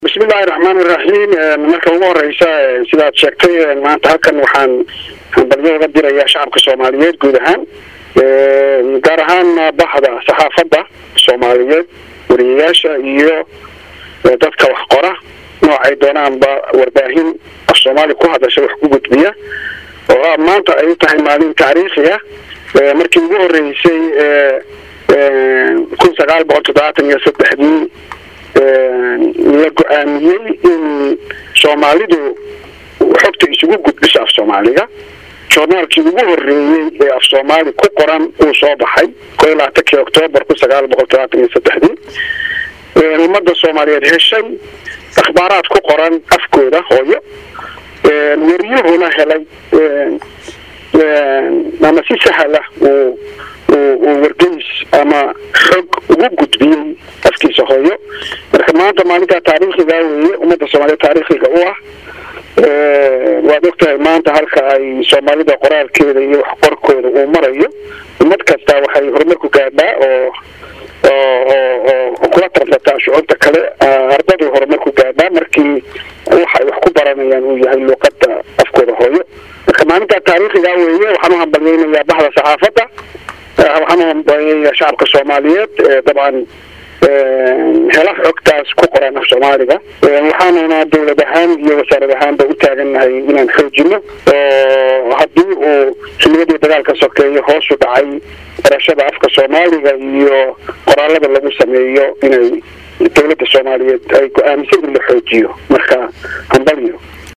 COD-WASIIR-MAAREEYE-1.mp3